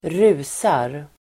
Uttal: [²r'u:sar]